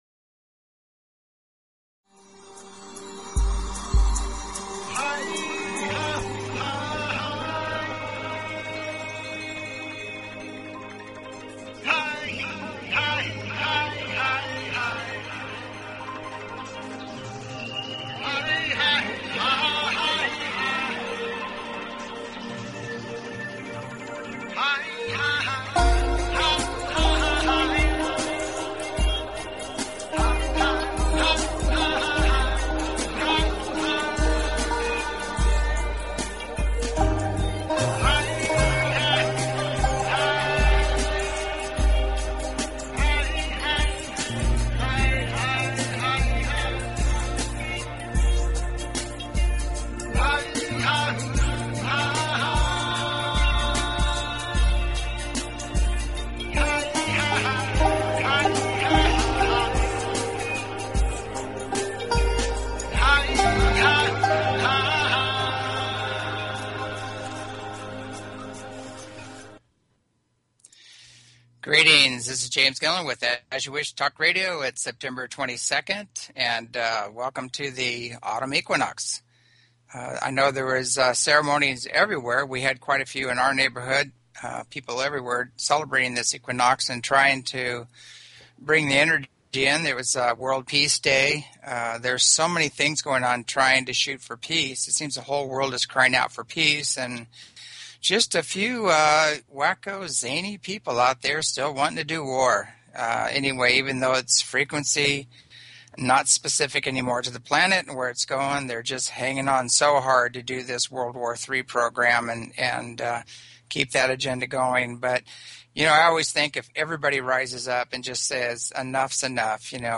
Talk Show Episode, Audio Podcast, As_You_Wish_Talk_Radio and Courtesy of BBS Radio on , show guests , about , categorized as
CALL INS, GUESTS AT ECETI, COVERING EVERYTHING FROM UFOS, EARTH CHANGES, VISIONS ETC
As you Wish Talk Radio, cutting edge authors, healers & scientists broadcasted Live from the ECETI ranch, an internationally known UFO & Paranormal hot spot.